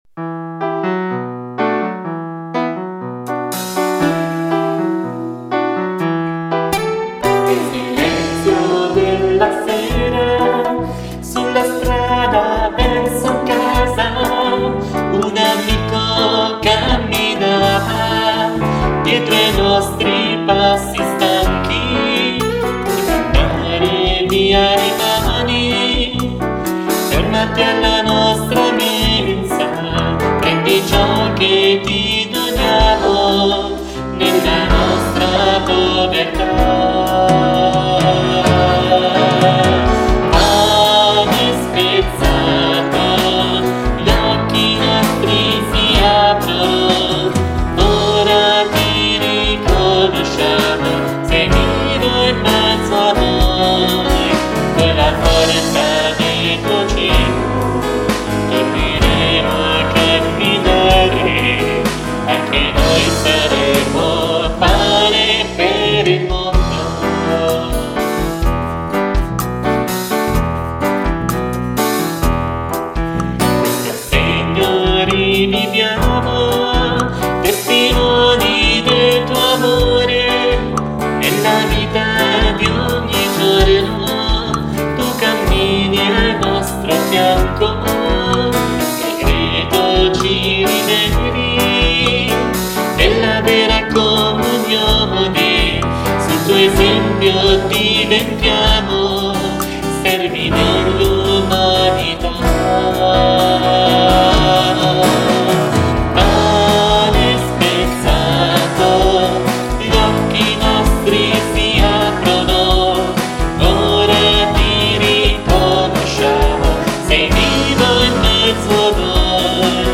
Audio del canto di Comunione, tratto dal sito diocesano: